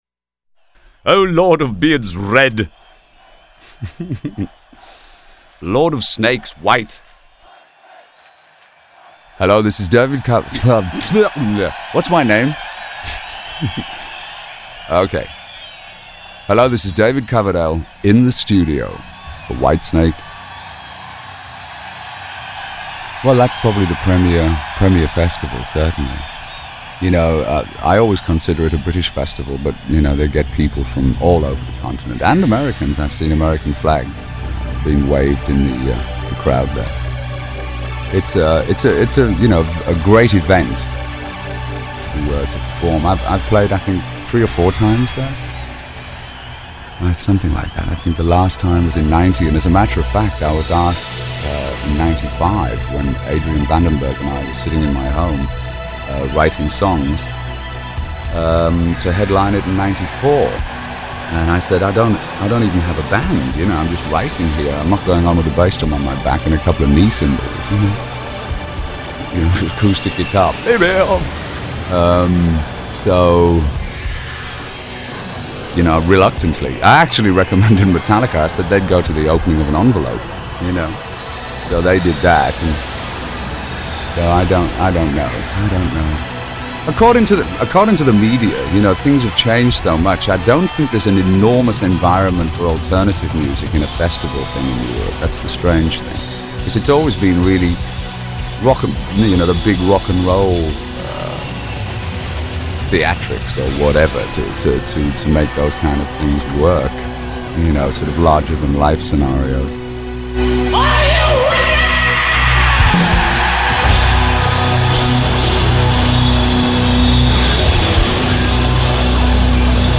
One of the world's largest classic rock interview archives
seven -string guitar
classic rock interview